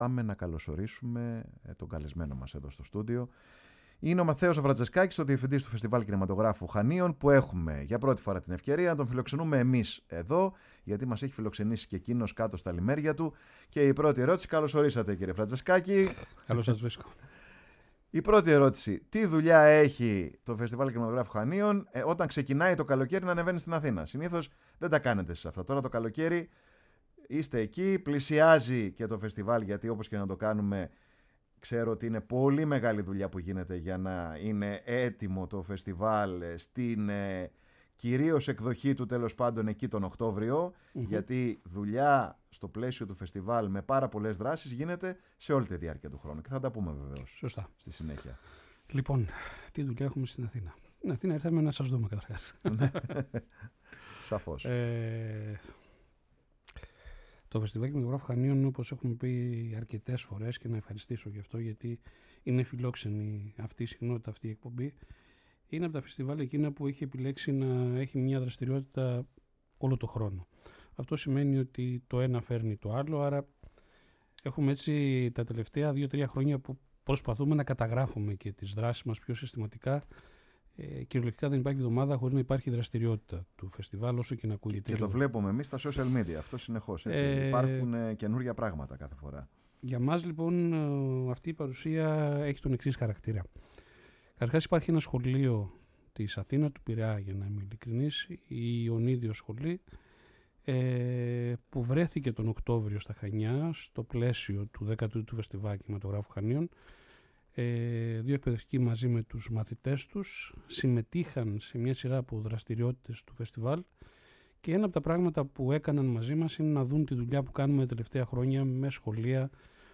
στο στούντιο